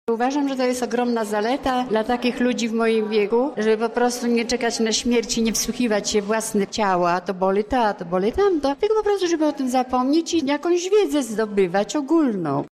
Lubelski Uniwersytet Trzeciego Wieku zainaugurował po raz 33 rok akademicki.